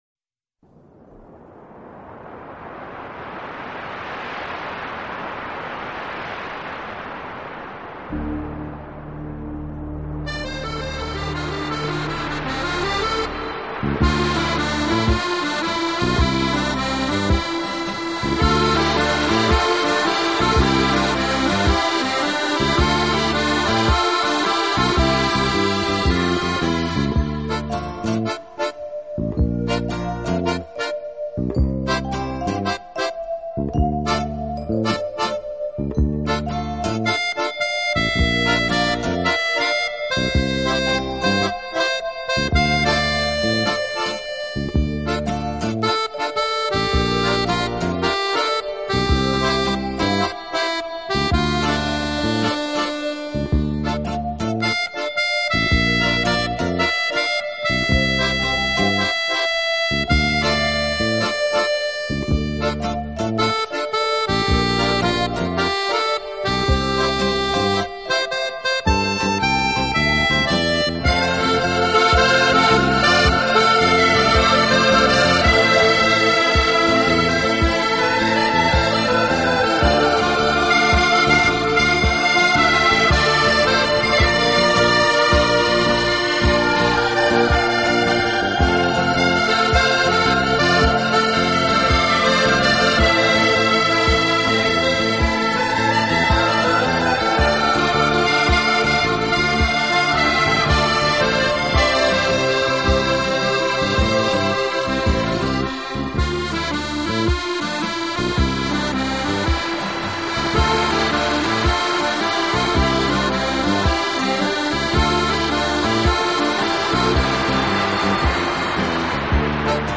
【顶级轻音乐】